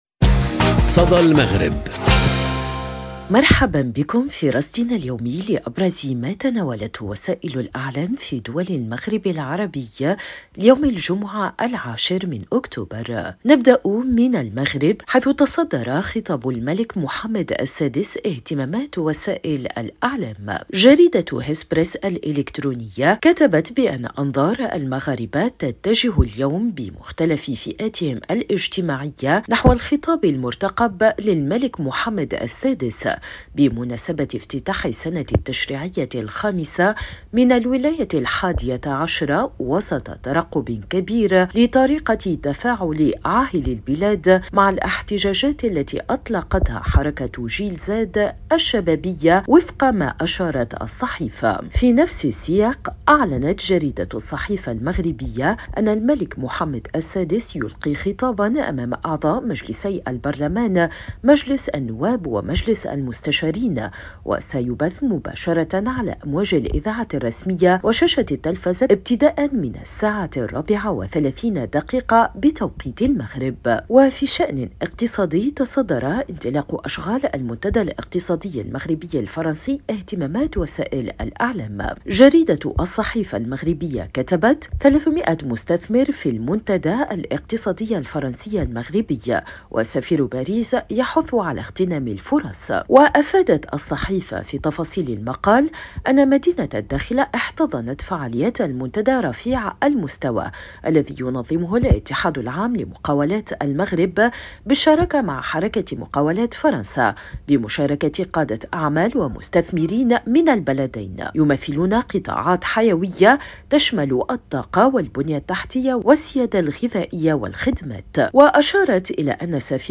صدى المغرب برنامج إذاعي يومي يُبث عبر راديو أوريان إذاعة الشرق، يسلّط الضوء على أبرز ما تناولته وسائل الإعلام في دول المغرب العربي، بما في ذلك الصحف، القنوات التلفزية، والميديا الرقمية.